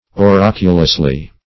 -- O*rac"u*lous*ly , adv.
oraculously.mp3